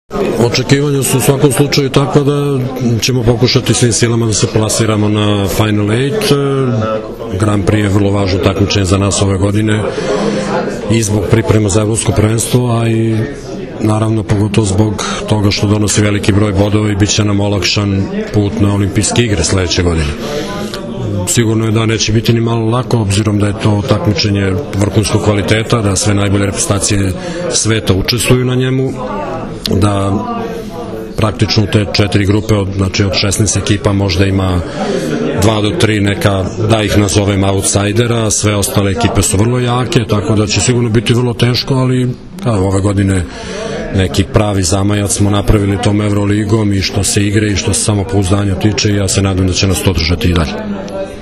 Tim povodom danas je u u beogradskom hotelu „M“ održana konferencija za novinare